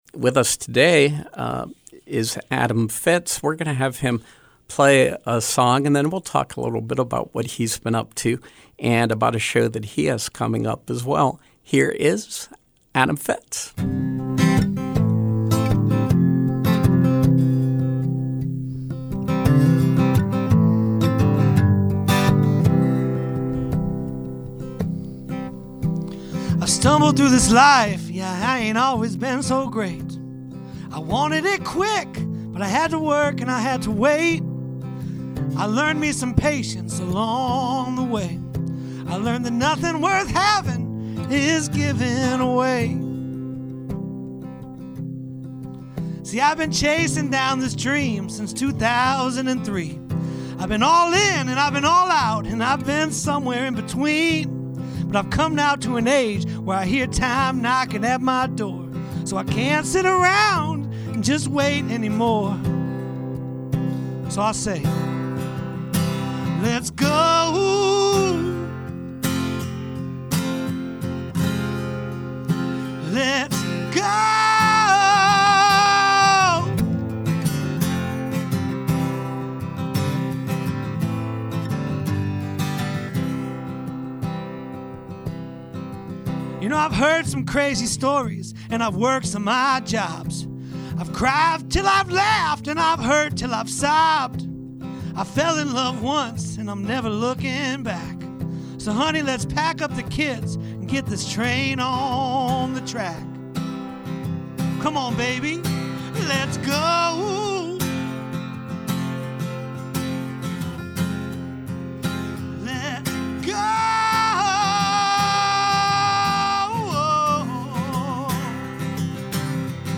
Live Music